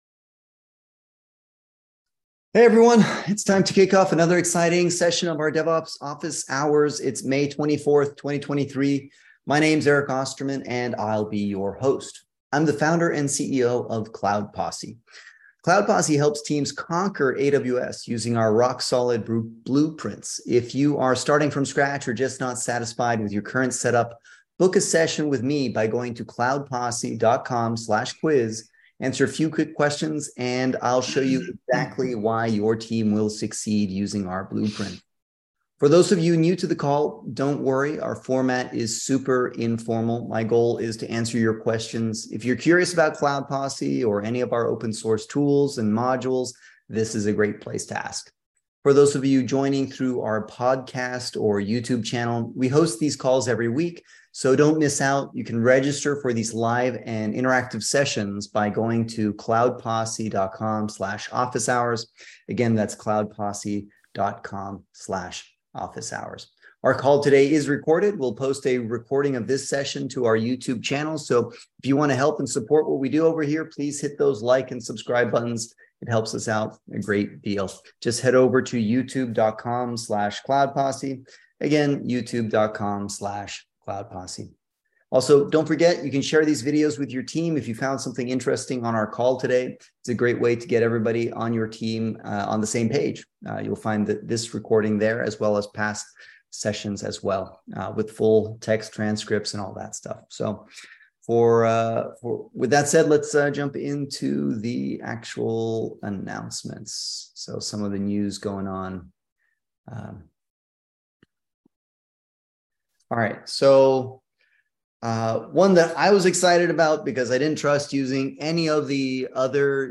Cloud Posse holds public "Office Hours" every Wednesday at 11:30am PST to answer questions on all things related to DevOps, Terraform, Kubernetes, CICD. Basically, it's like an interactive "Lunch & Learn" session where we get together for about an hour and talk shop.